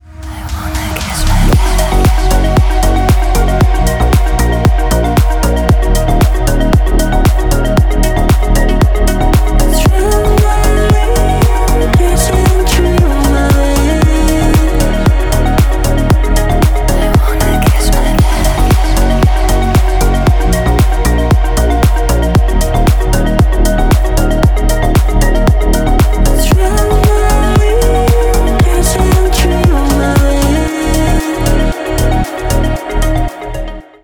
• Качество: 320, Stereo
громкие
deep house
мелодичные
чувственные
нежные